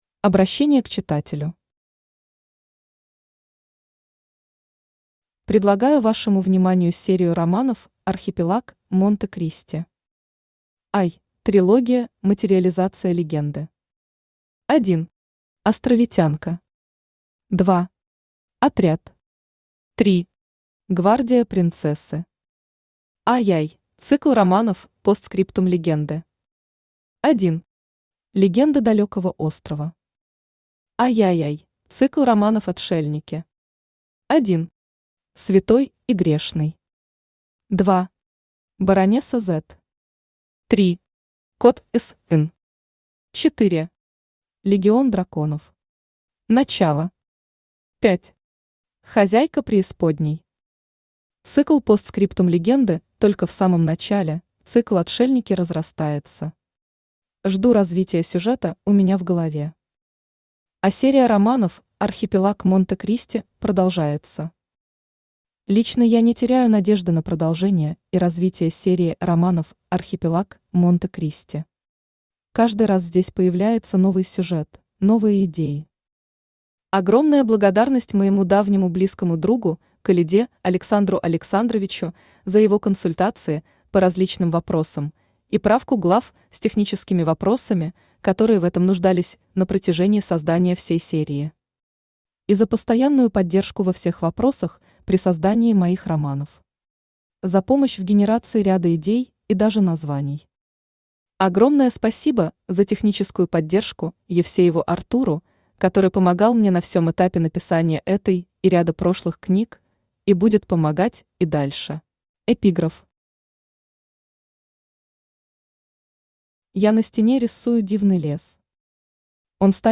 Том 4 Автор Геннадий Анатольевич Бурлаков Читает аудиокнигу Авточтец ЛитРес.